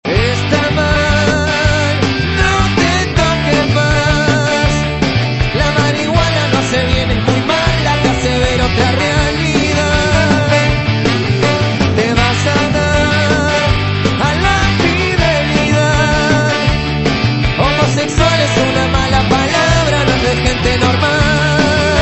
Pop & Rock
Rock Argentino